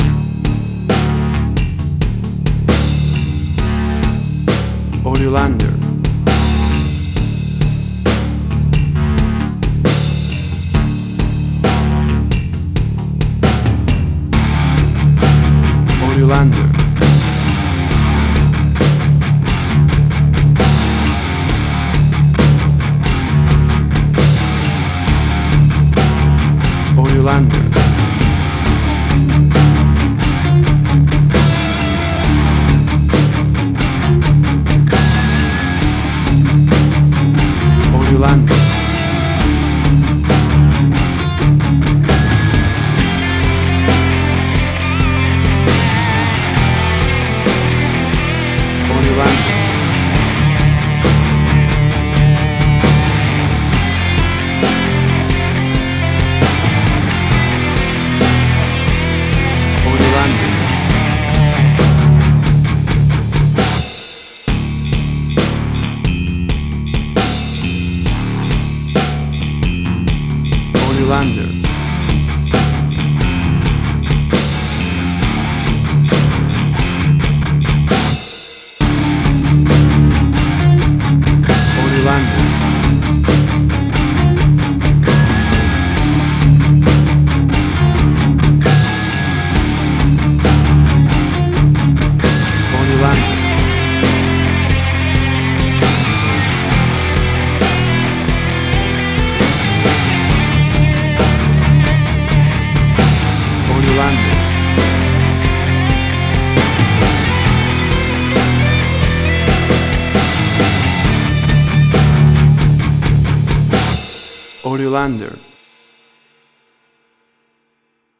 Hard Rock
Heavy Metal
Tempo (BPM): 67